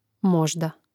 mȍžda možda pril.